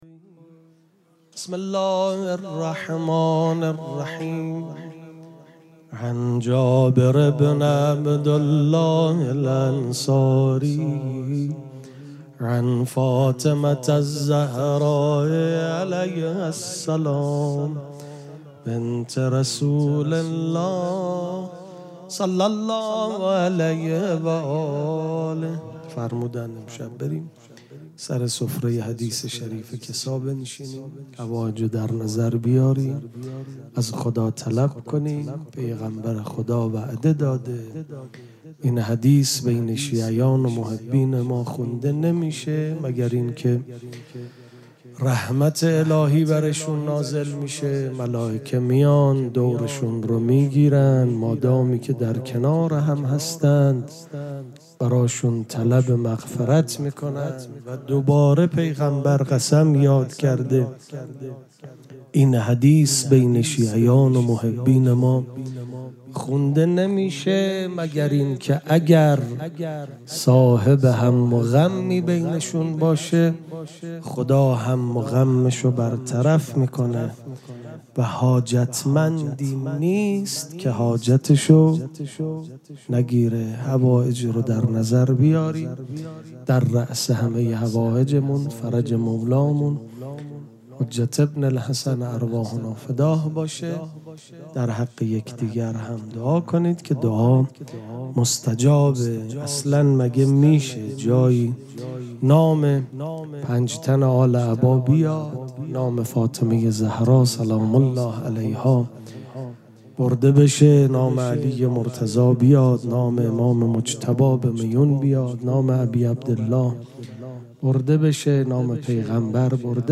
ذکر توسل
شب اول مراسم جشن ولادت سرداران کربلا
سبک اثــر پیش منبر